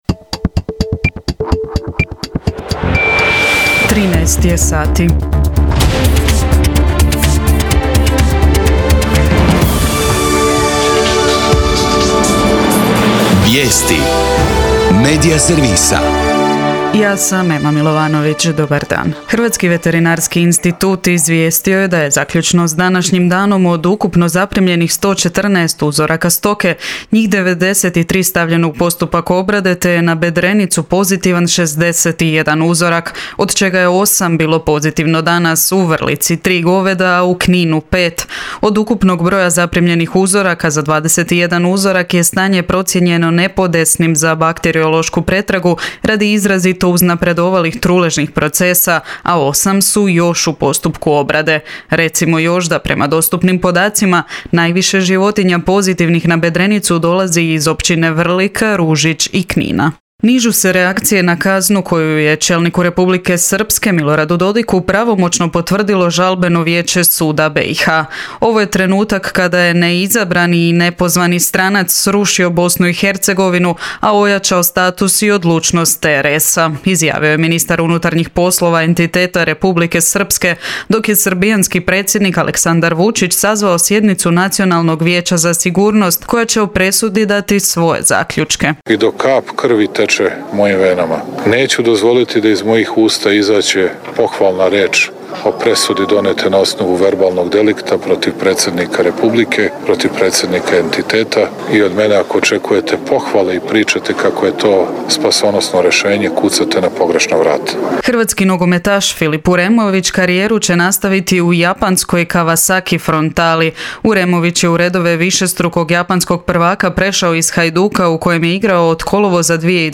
VIJESTI U 13